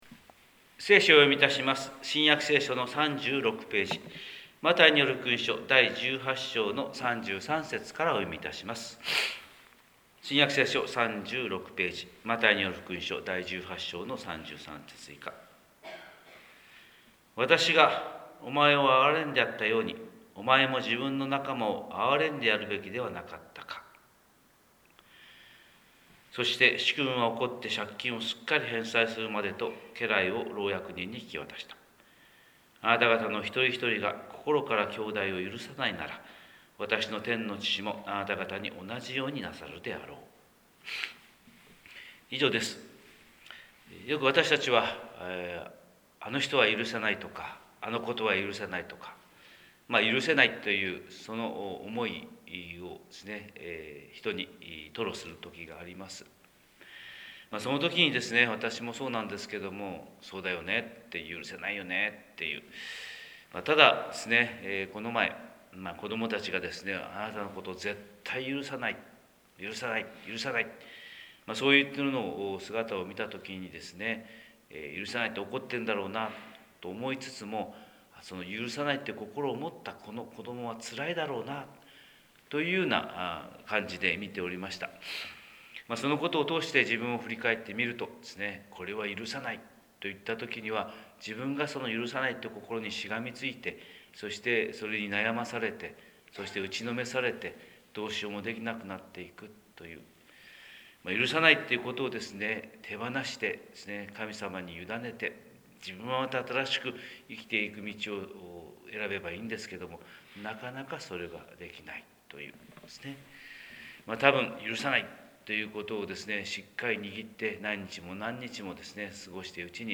神様の色鉛筆（音声説教）: 広島教会朝礼拝250401